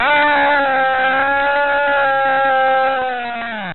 PERSON-Scream+6
Tags: combat